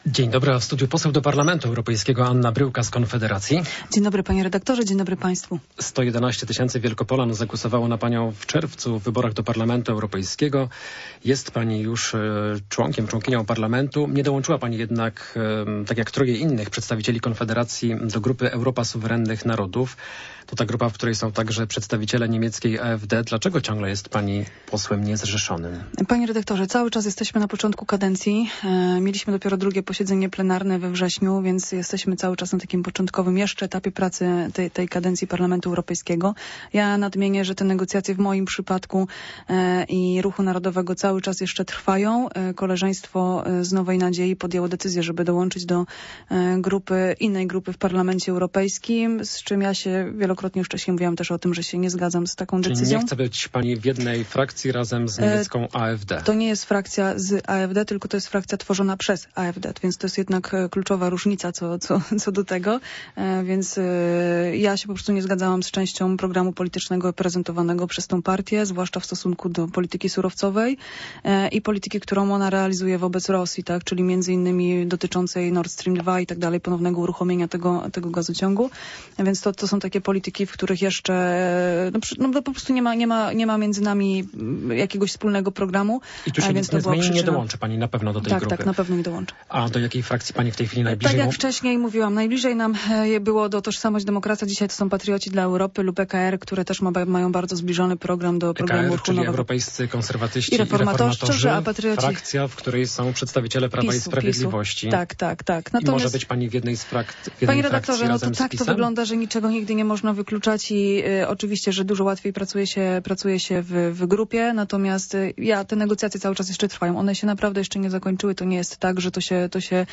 Rozmowa popołudniowa - Anna Bryłka - 25.09.2024